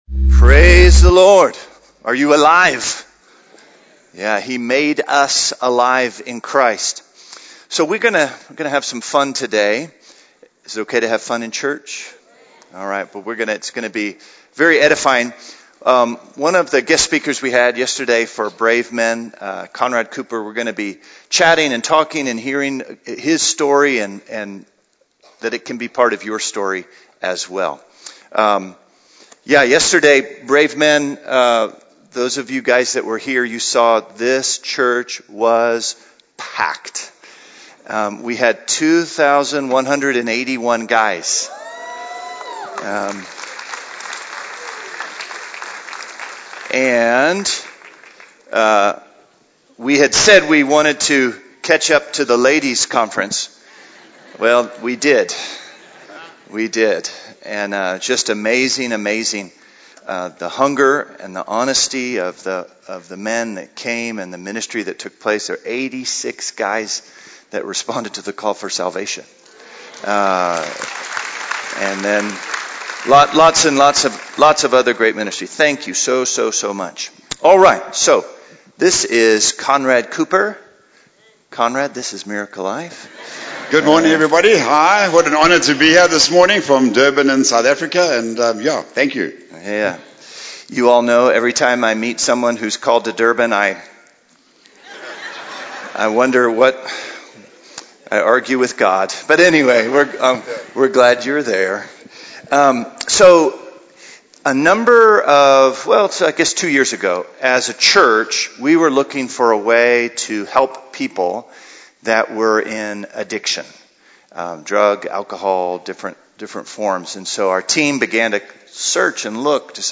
A message from the series "Individual Sermons."